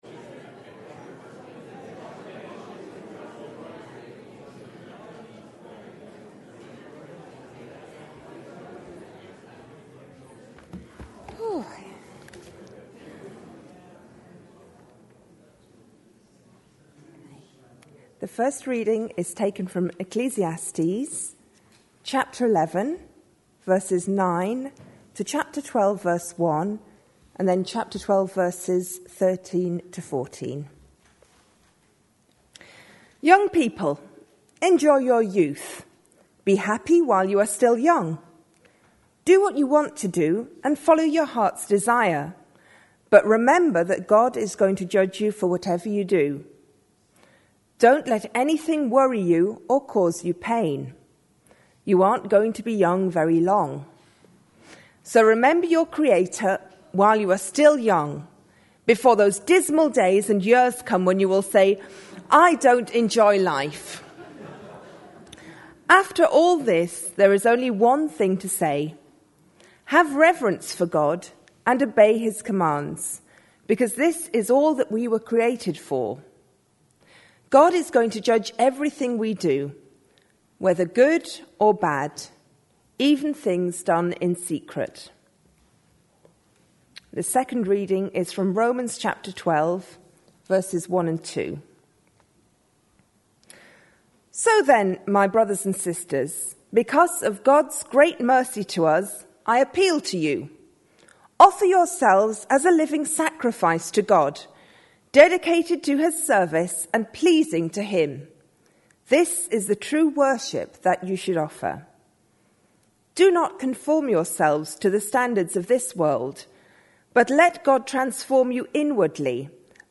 A sermon preached on 25th April, 2010, as part of our Love Actually series.